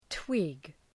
twig.mp3